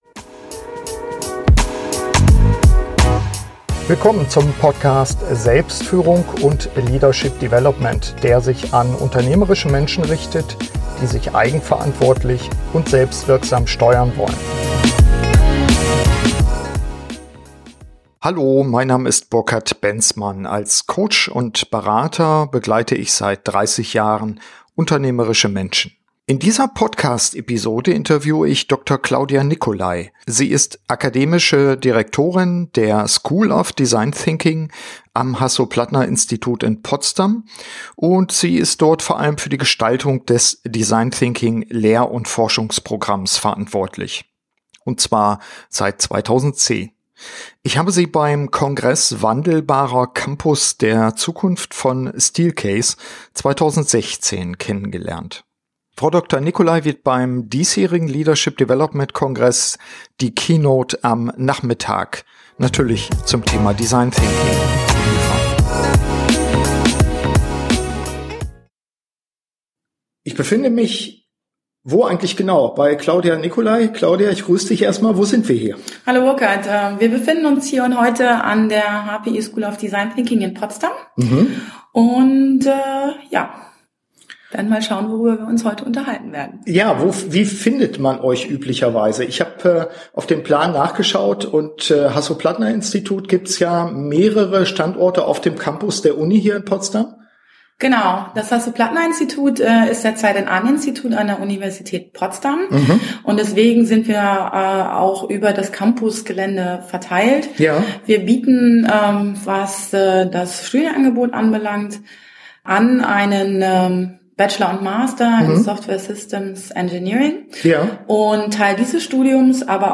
Warum Design Thinking vor allem bedeutet, sich auf andere "Mitdenker" einzulassen und wie die Zukunft dieses Innovations-Tools aussieht - diese und andere Erkenntnisse im Podcast-Interview